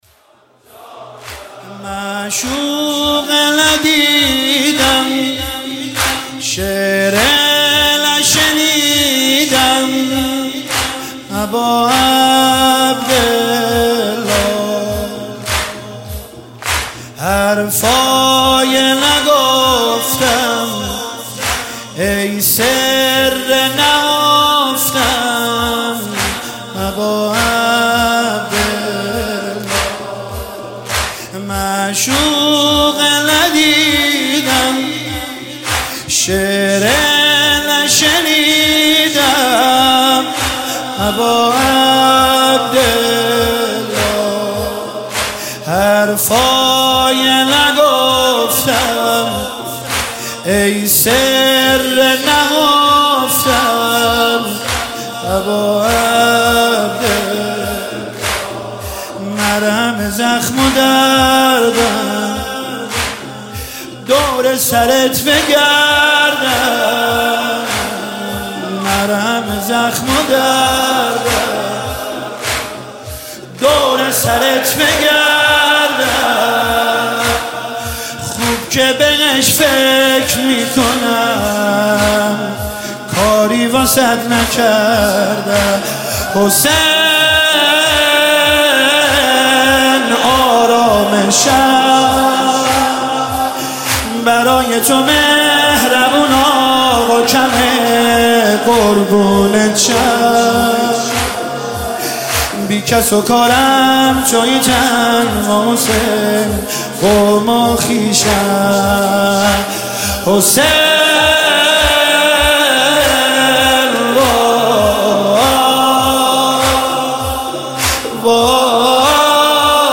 مداحی دلنشین